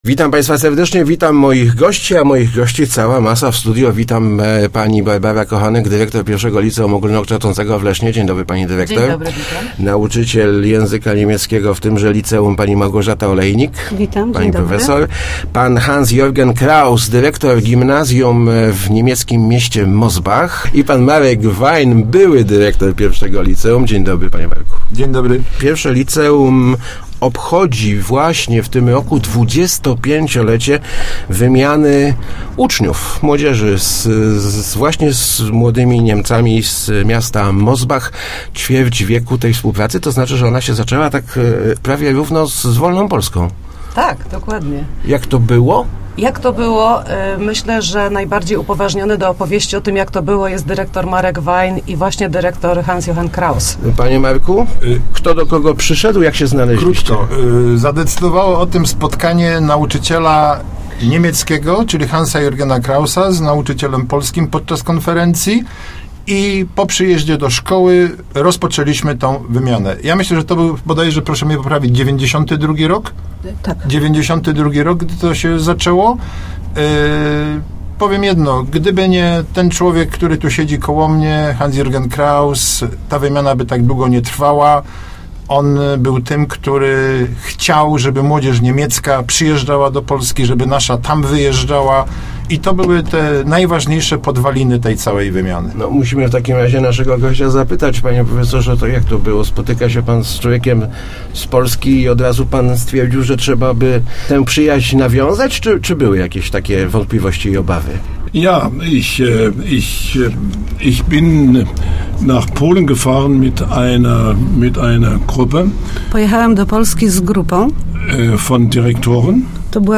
O początkach wymiany i obecnym jej kształcie opowiadali w Rozmowach Elki